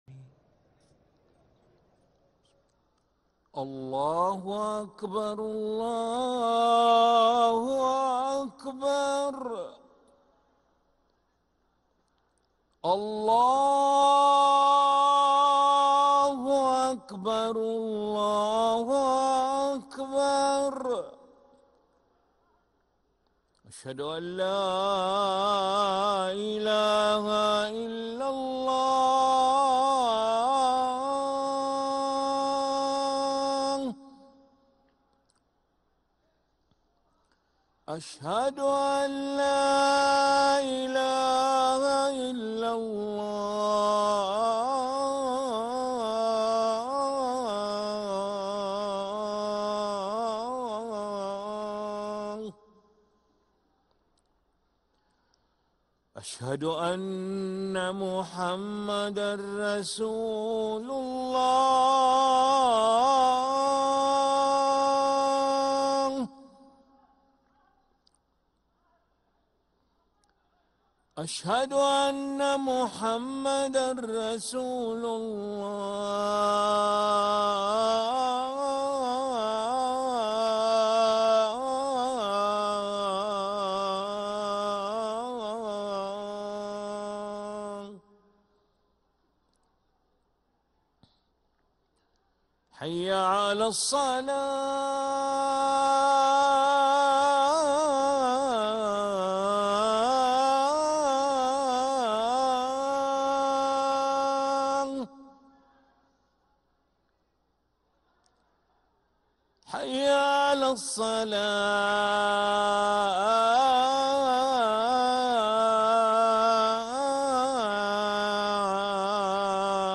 أذان العشاء للمؤذن علي ملا الخميس 16 ربيع الأول 1446هـ > ١٤٤٦ 🕋 > ركن الأذان 🕋 > المزيد - تلاوات الحرمين